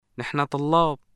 [nəħna Təllaab]